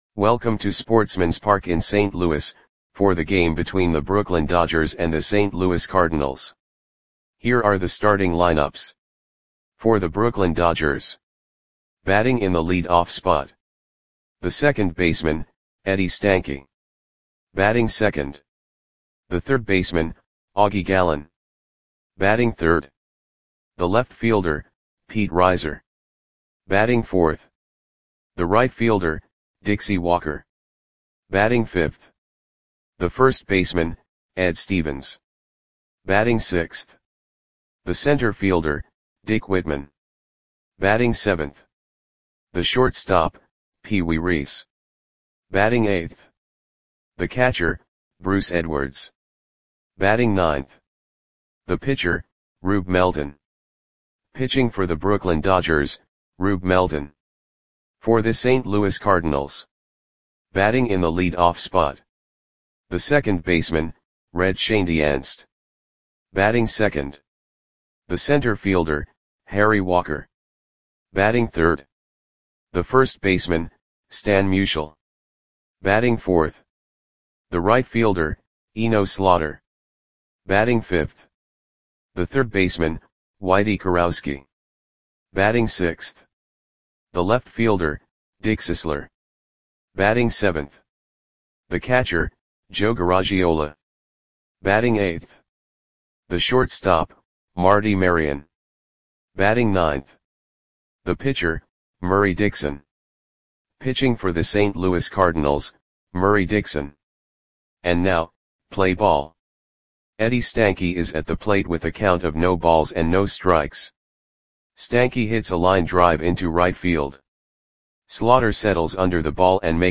Audio Play-by-Play for St. Louis Cardinals on August 26, 1946
Click the button below to listen to the audio play-by-play.